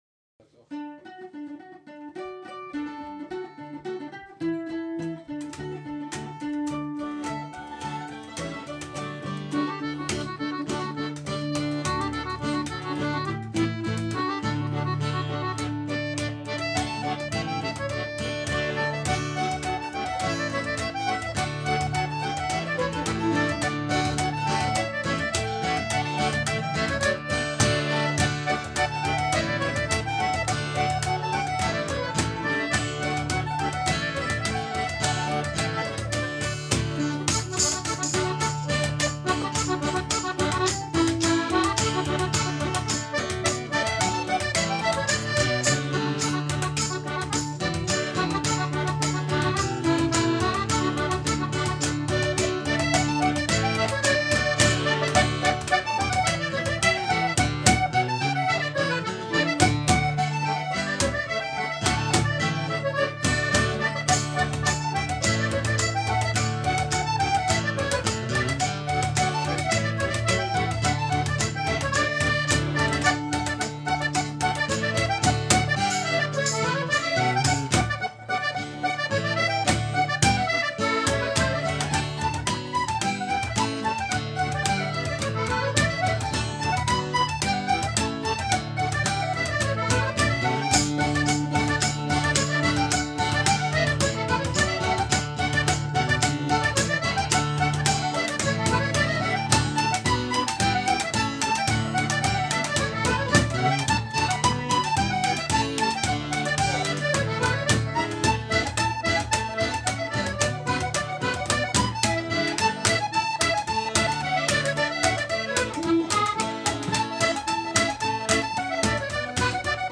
Shenanigan, The Celtic Party Band out for the craic
Irish & Party music in The South of England.